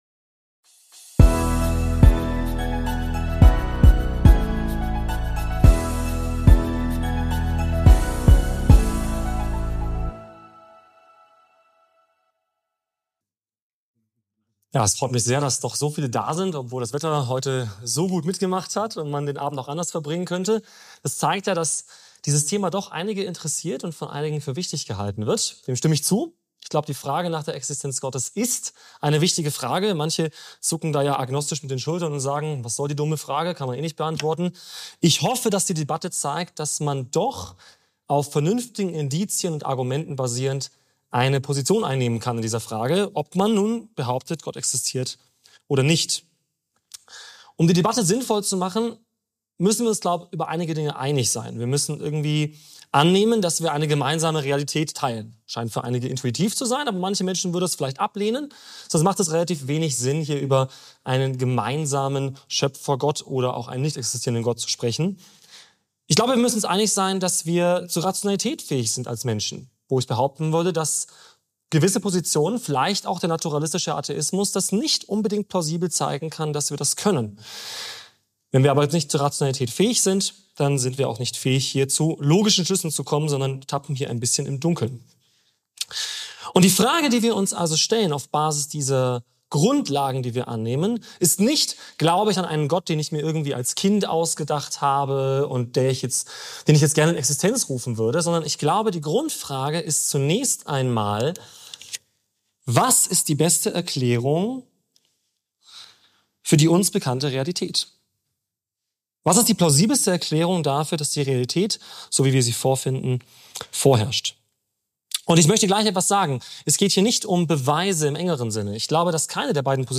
Debatte: Es gibt (keinen) Gott?!